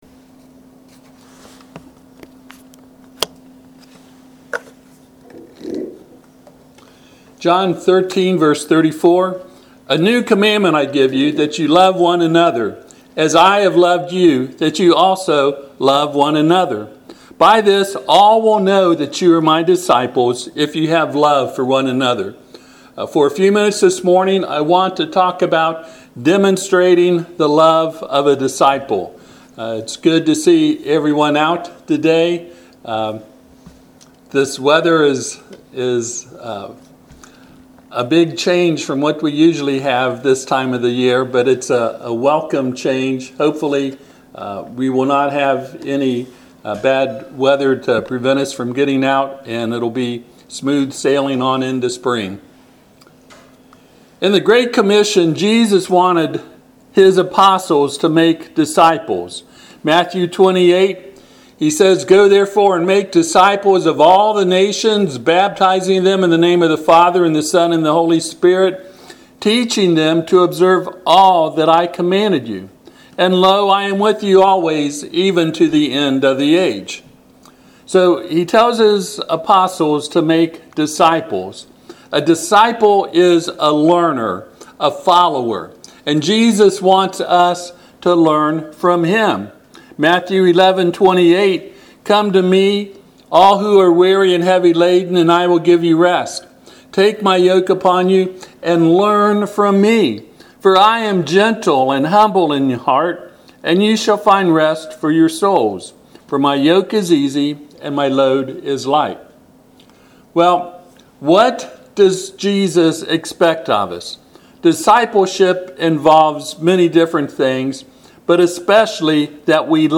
Passage: John 13:34-35 Service Type: Sunday AM